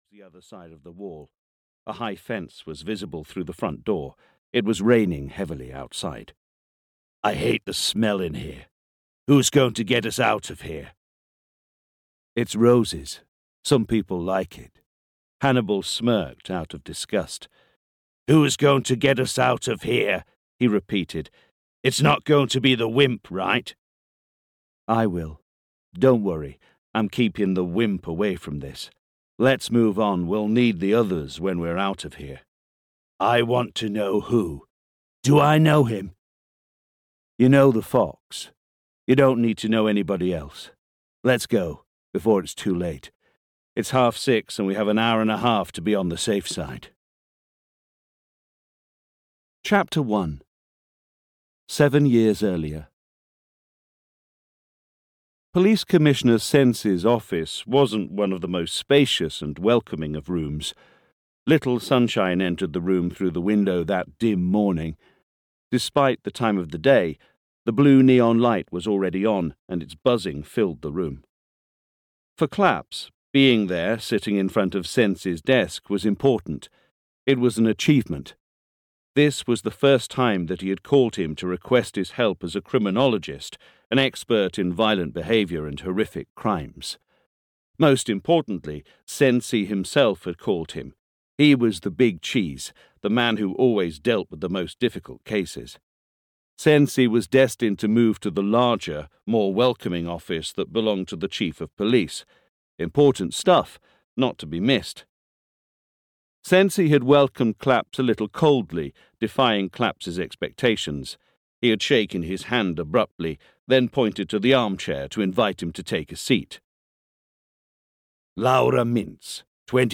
Audio knihaHunted (EN)
Ukázka z knihy